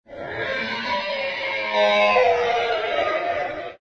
Descarga de Sonidos mp3 Gratis: miedo 28.